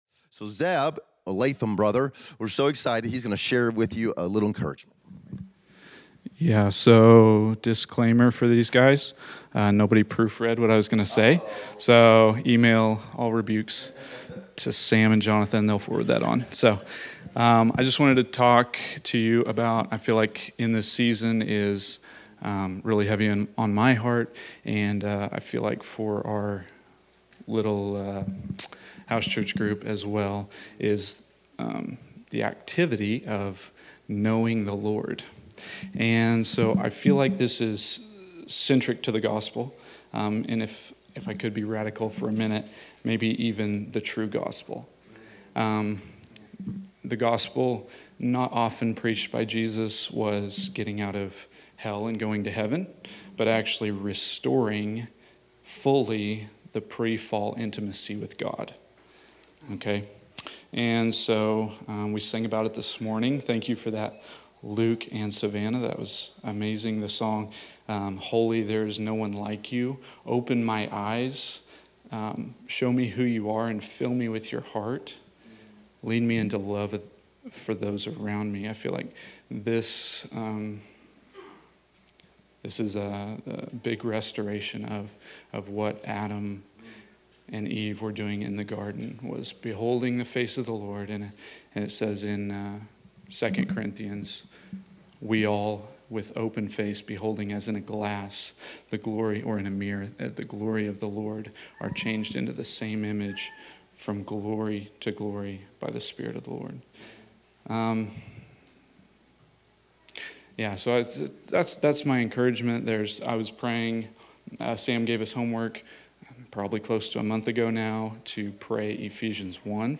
Category: Encouragements